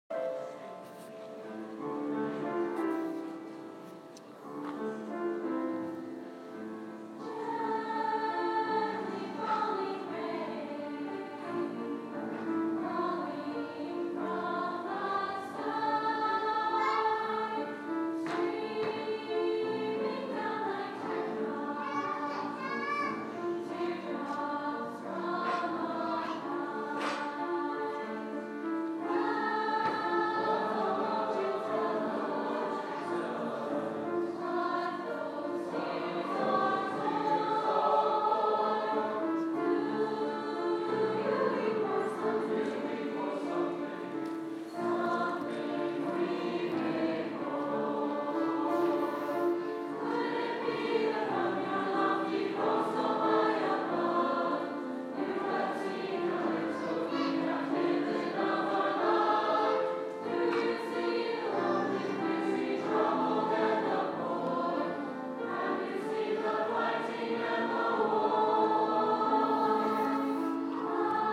combined choir 11-2-15